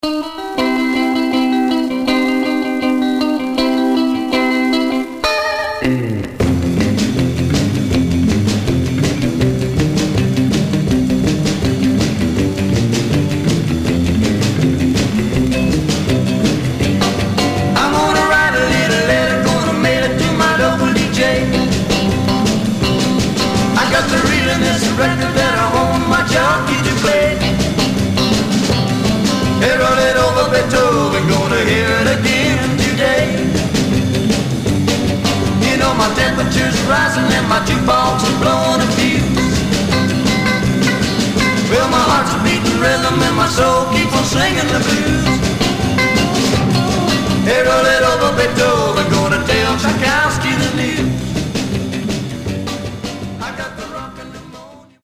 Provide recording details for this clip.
Some surface noise/wear Mono